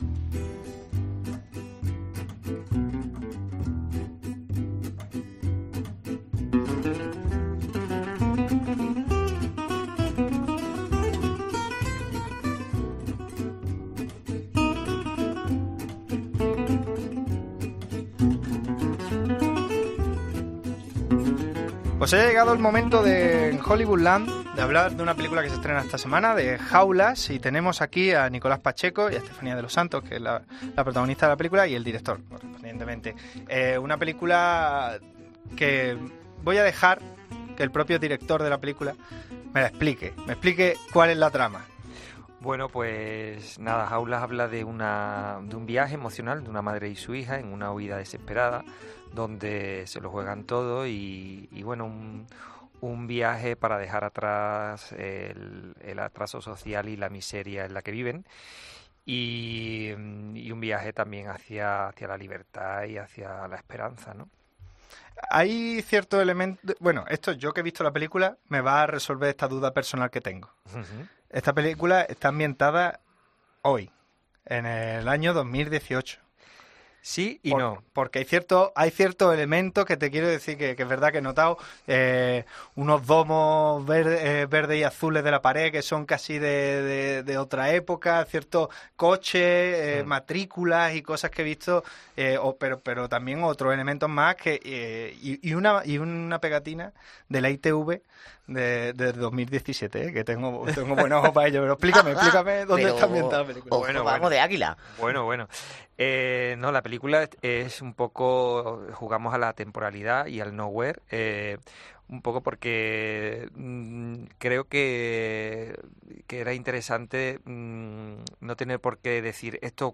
ha estado también en los estudios de COPE